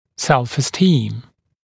[ˌselfɪ’stiːm][ˌсэлфи’сти:м]самоуважение, чувство собственного достоинства